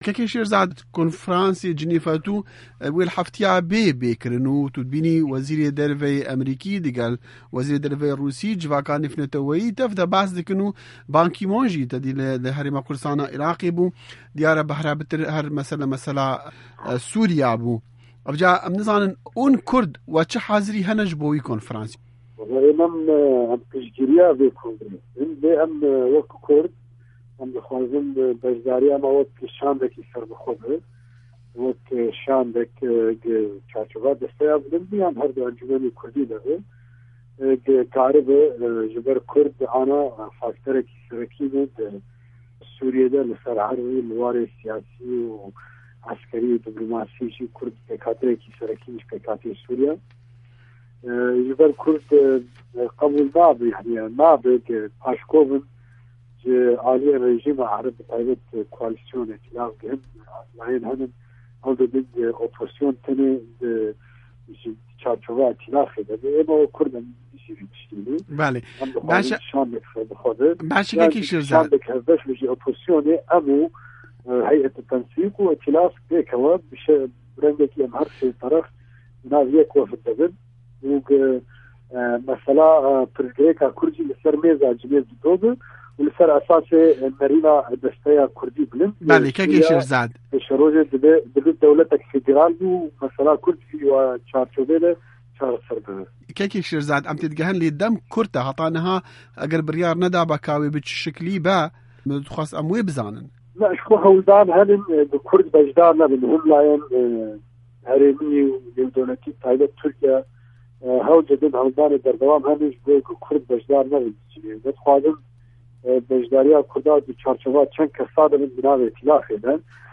Di hevpeyvînekê de ligel Dengê Amerîka